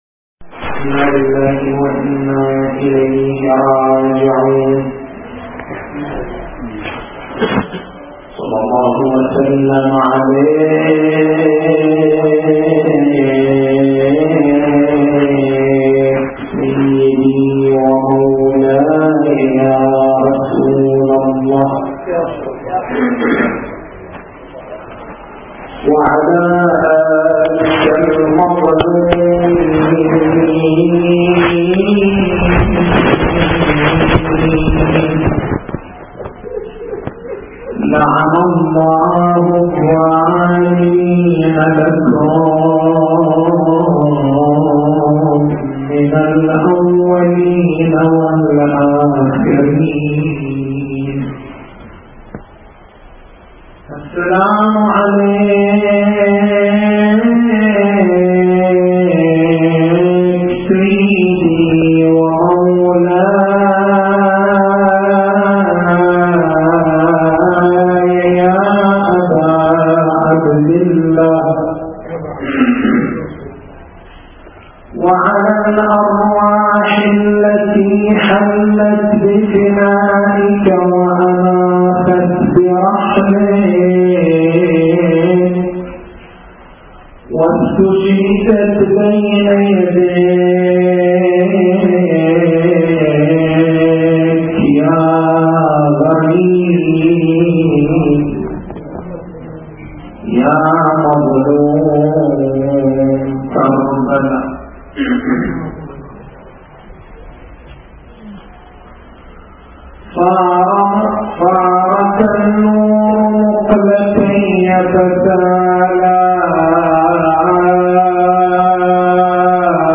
تاريخ المحاضرة: 01/01/1426 نقاط البحث: فلسفة الشعار في الإسلام دور الشعار في واقعة الطف دروس مستفادة من شعارات يوم كربلاء التسجيل الصوتي: تحميل التسجيل الصوتي: شبكة الضياء > مكتبة المحاضرات > محرم الحرام > محرم الحرام 1426